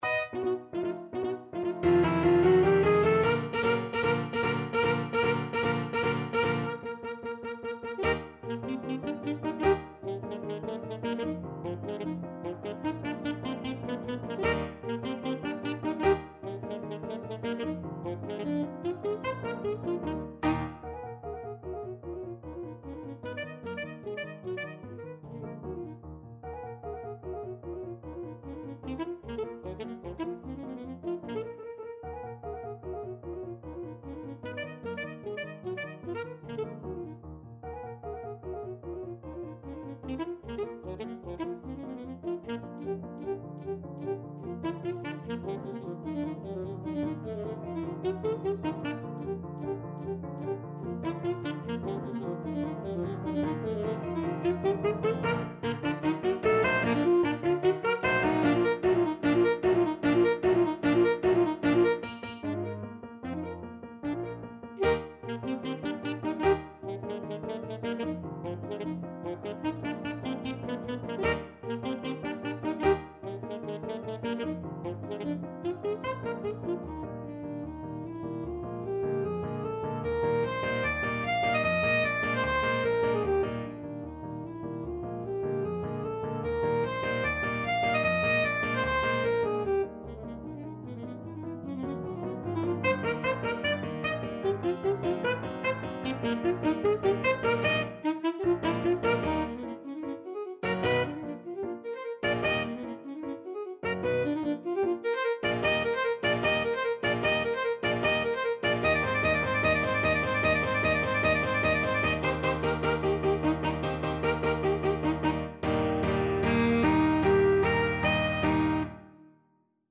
Classical Ponchielli, Amilcare Galop from Dance of the Hours (from La Gioconda) Alto Saxophone version
Eb major (Sounding Pitch) C major (Alto Saxophone in Eb) (View more Eb major Music for Saxophone )
2/4 (View more 2/4 Music)
Allegro vivacissimo ~ = 150 (View more music marked Allegro)
Classical (View more Classical Saxophone Music)
galop_dance_of_the_hours_ASAX.mp3